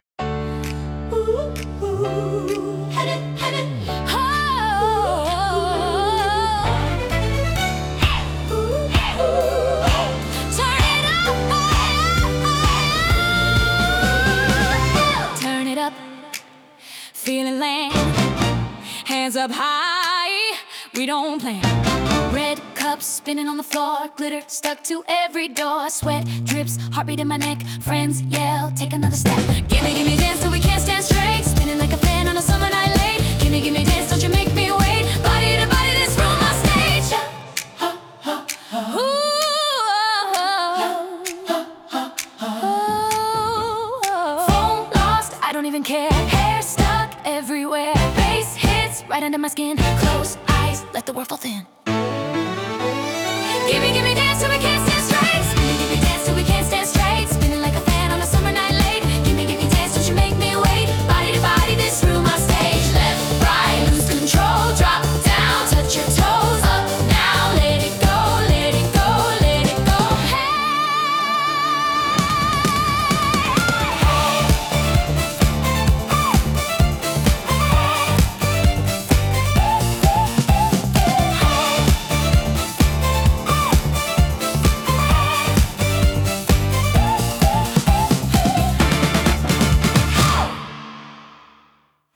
70年代の煌びやかなユーロ・ディスコポップ！